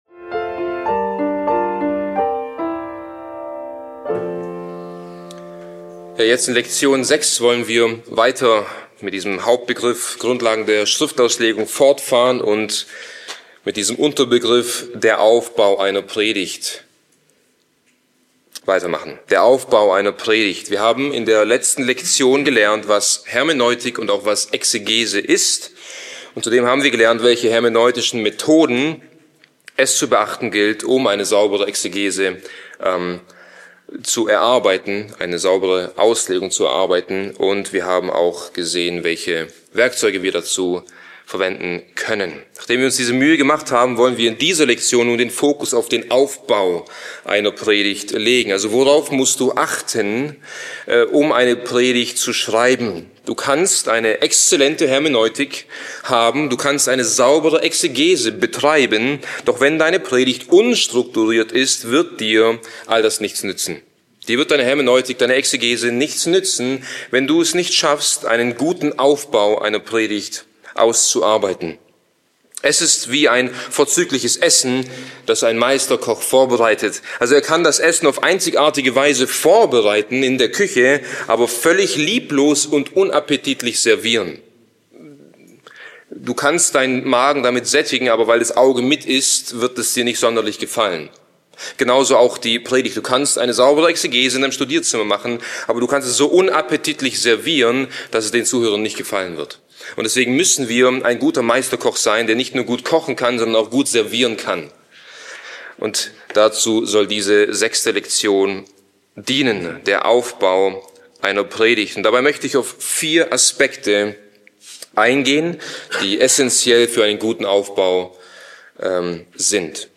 Dies ist die sechste von insgesamt acht Lektionen, über das Amt des Predigens.